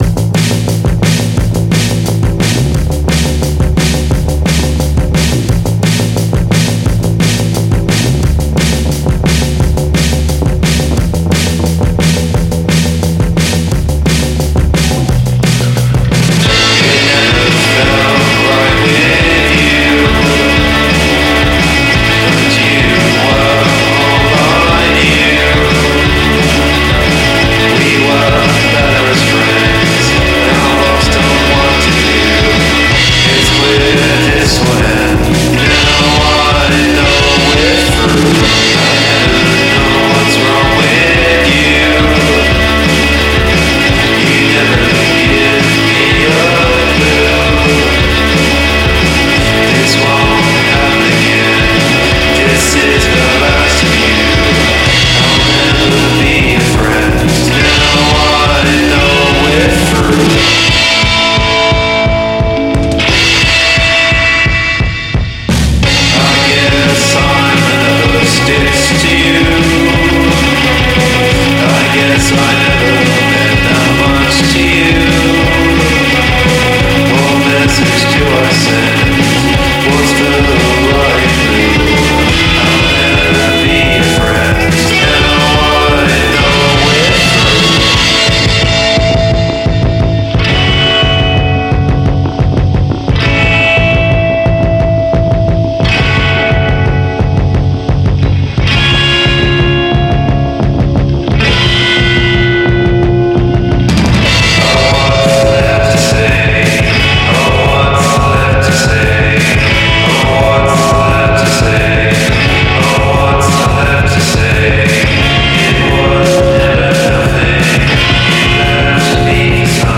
dato il marchio di bassa fedeltà della band inglese